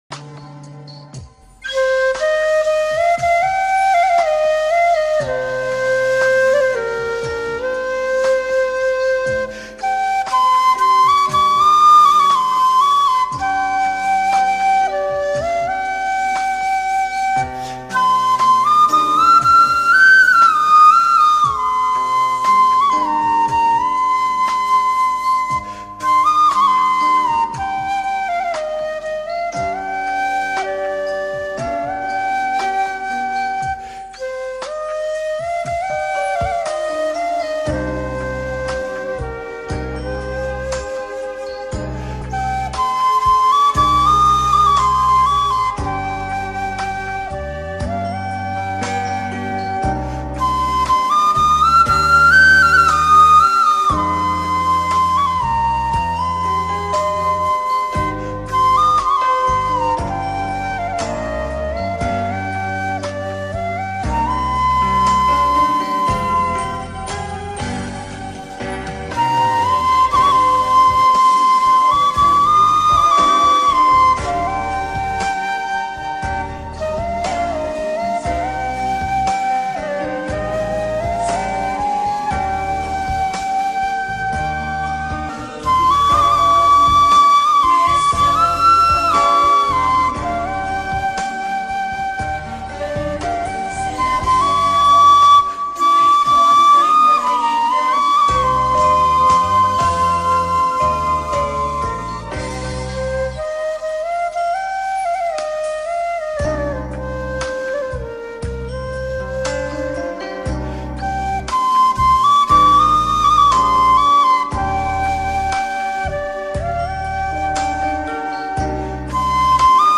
giai điệu dân tộc sâu lắng và cảm động.
bản không lời chất lượng cao